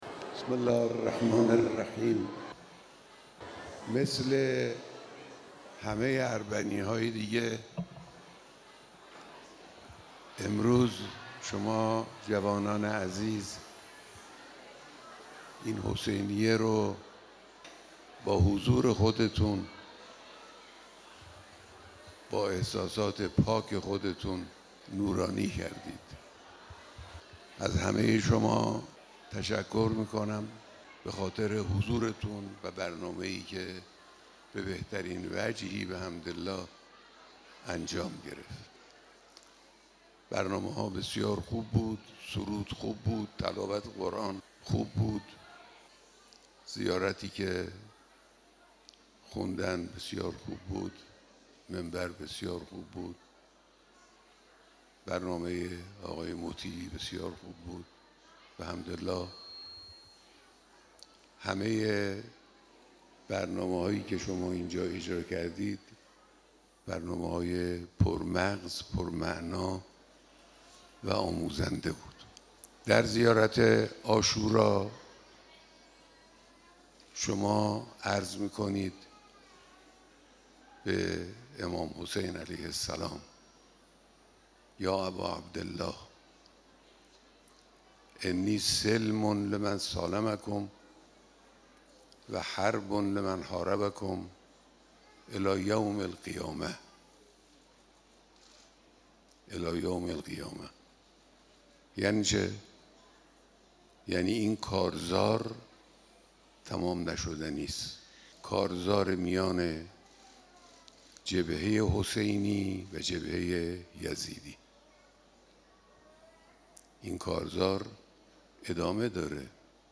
بیانات در مراسم عزاداری دانشجویان به‌مناسبت اربعین حسینی علیه‌السلام